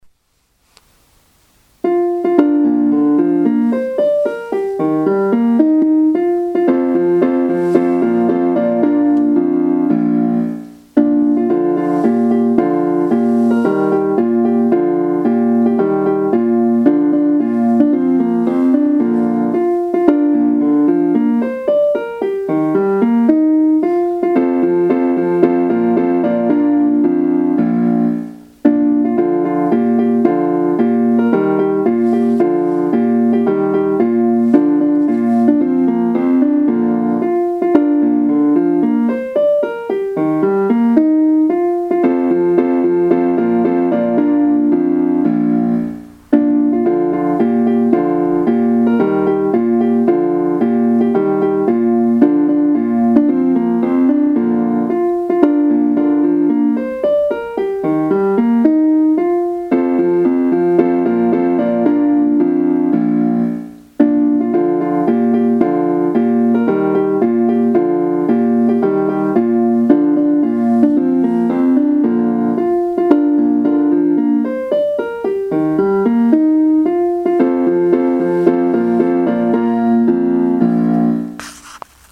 さて、ホームページの右側の配布文書のところに 内浦小の校歌（伴奏のみ）をアップしました。